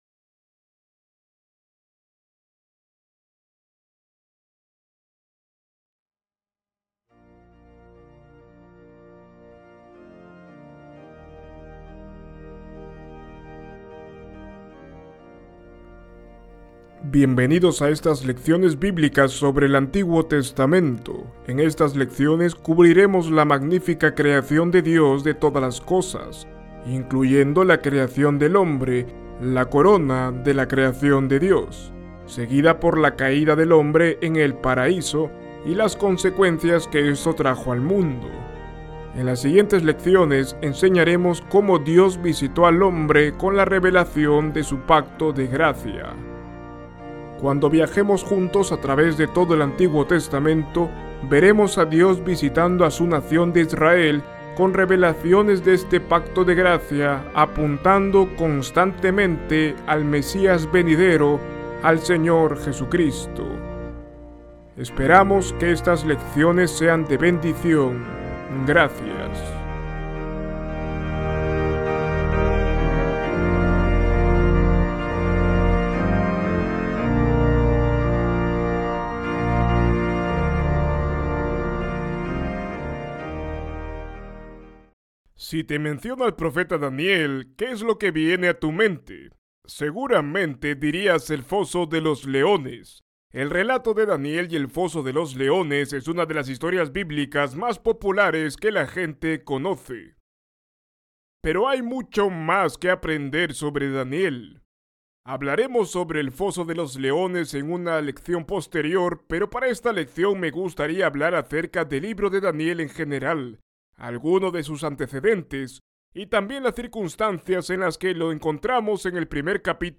En esta lección, veremos todas las cosas que les pasaron. Ver video Descargar video MP4 Escuchar lección Descargar audio en mp3 Ver transcripción en PDF Descargar transcripción en PDF Guia de Estudio